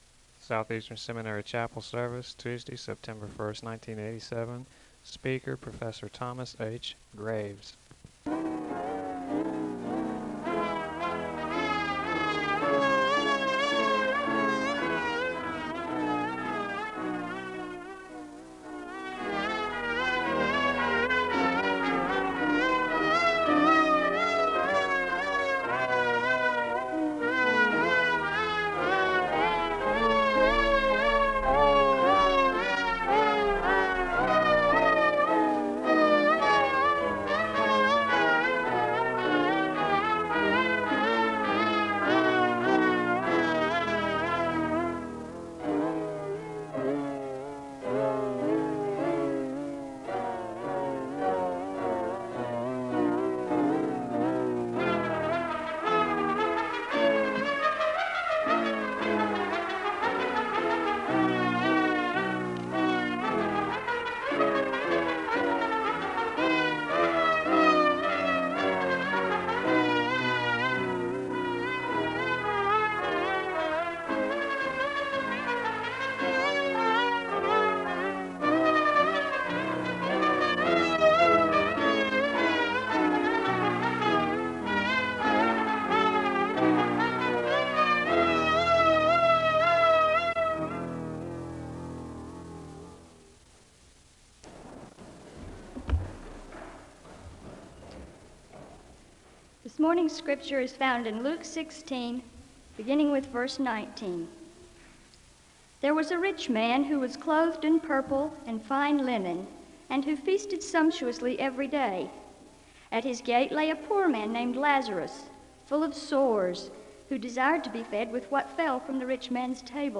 The service begins with orchestral music (0:00-1:36). There is a Scripture reading from Luke (1:37-3:29). There is a moment of prayer (3:30-5:04). The choir sings a song of worship (5:05-6:46).
SEBTS Chapel and Special Event Recordings SEBTS Chapel and Special Event Recordings